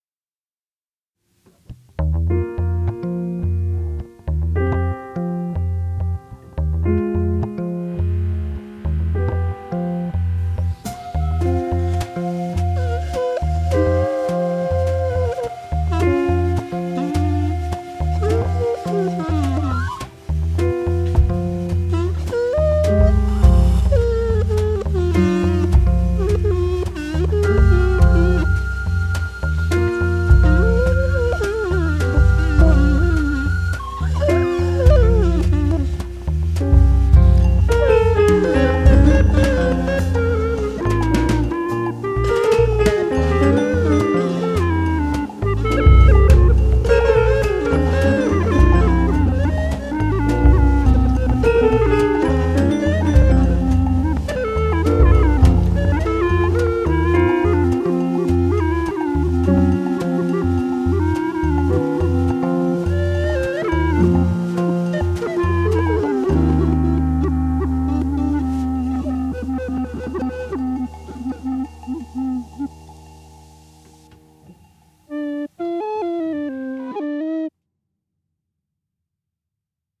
Recorder with and without mouthpiece. Recorder with octaver and distortion
Blockflöte_Gone_Crazy.mp3